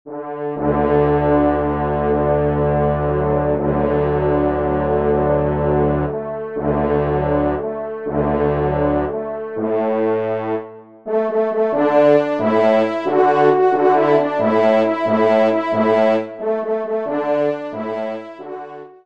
Pupitre  5°Trompe